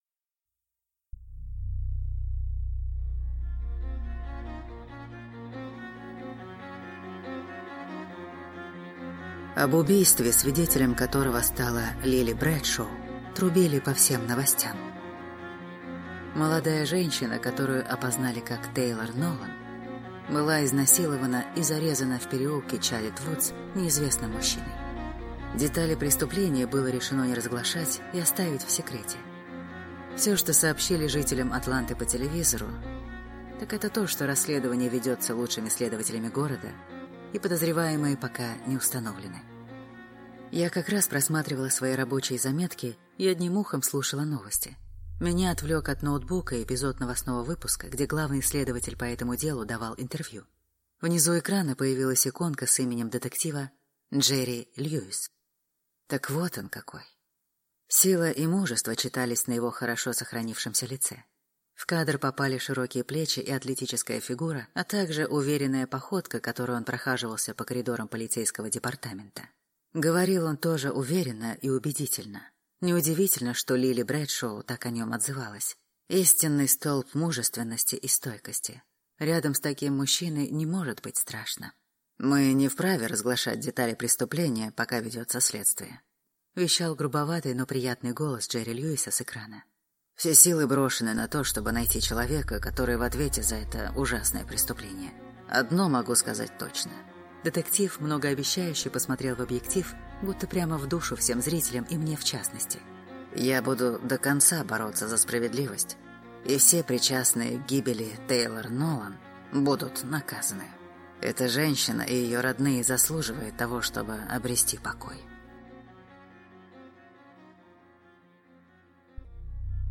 Аудиокнига Лицо смерти | Библиотека аудиокниг